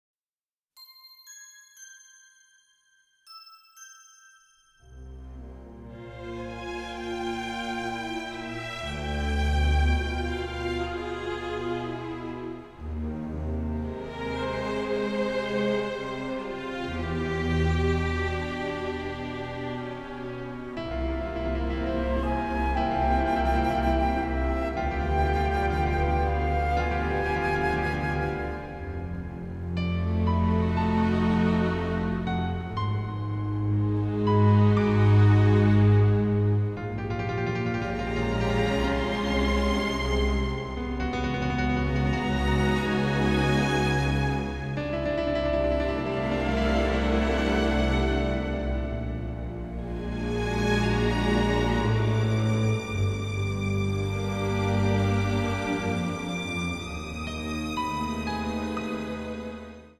suspense score